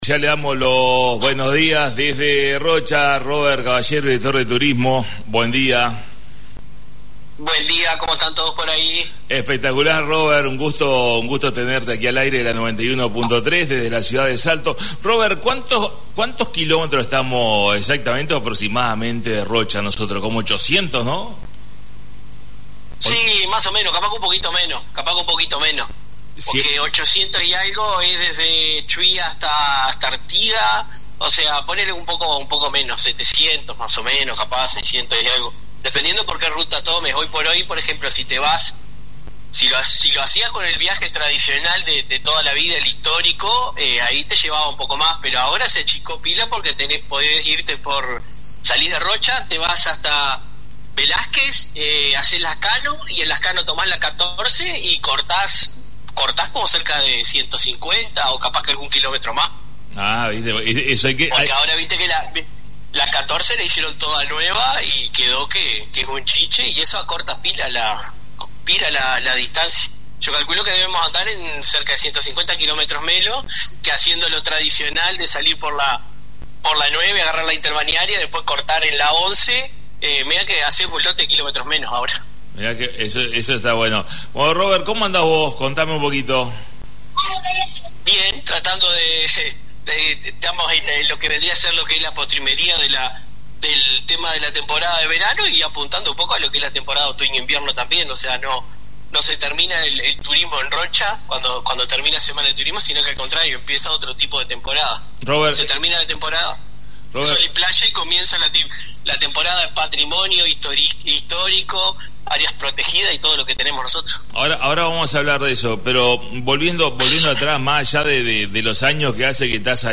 Comunicación en vivo con Robert Caballero Director de Turismo Intendencia de Rocha